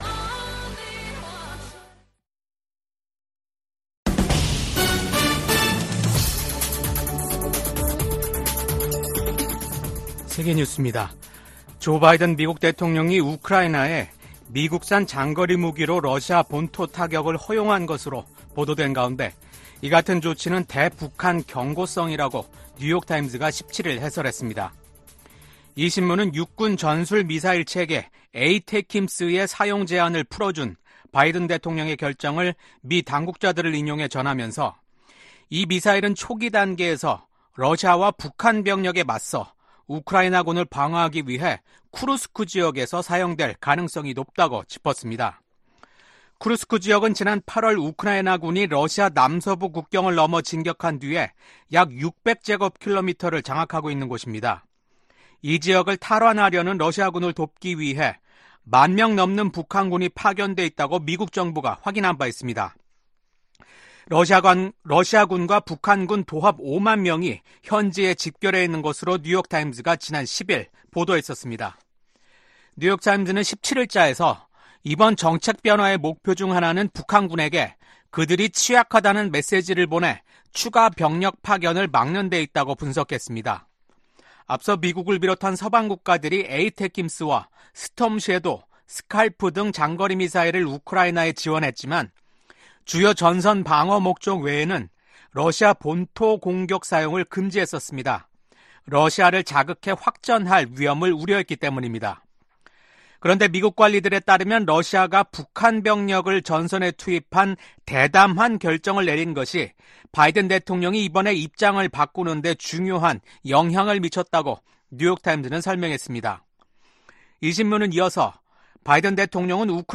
VOA 한국어 아침 뉴스 프로그램 '워싱턴 뉴스 광장'입니다. 미국과 한국, 일본의 정상이 북한군의 러시아 파병을 강력히 규탄했습니다. 디미트로 포노마렌코 주한 우크라이나 대사가 VOA와의 단독 인터뷰에서 러시아와 우크라이나 간 격전지인 러시아 쿠르스크에 북한군 장군 7명이 파병됐다고 밝혔습니다.